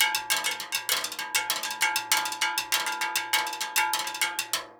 Index of /90_sSampleCDs/USB Soundscan vol.36 - Percussion Loops [AKAI] 1CD/Partition B/03-100STEELW